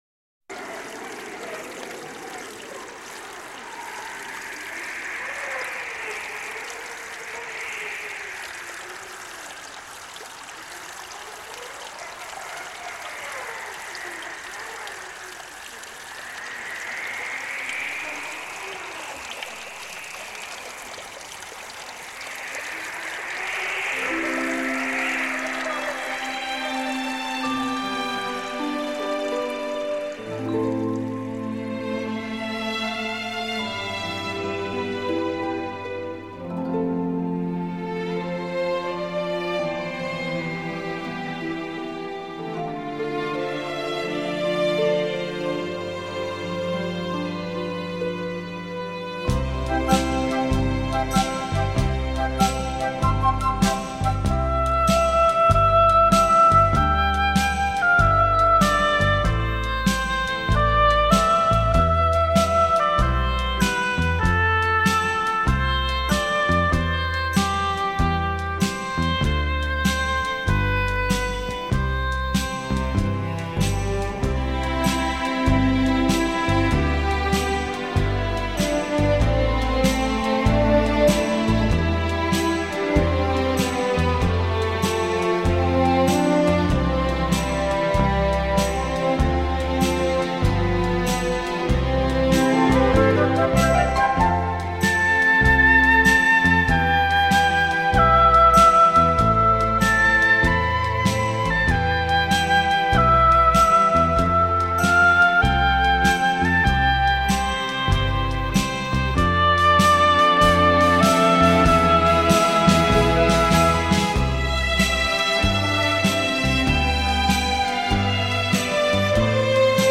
音乐类型：新世纪音乐(NEW AGE)